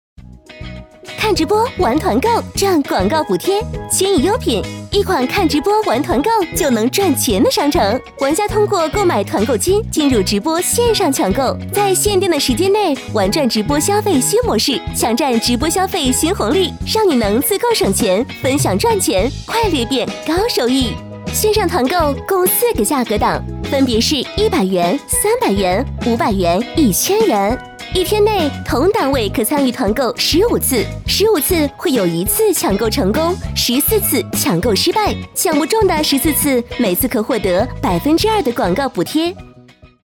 飞碟说-女16-欢快轻松 团购商城.mp3